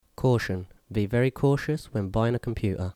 3 Caution ˈkɔːʃən